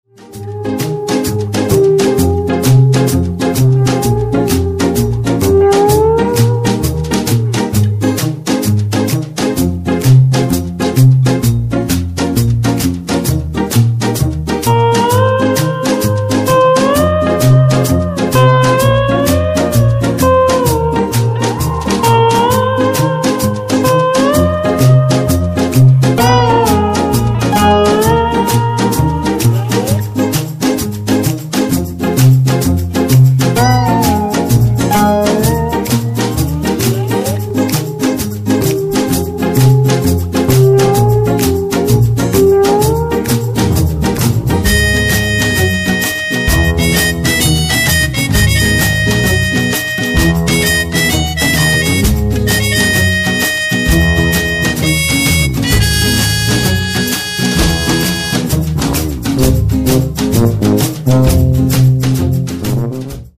трек из х-ф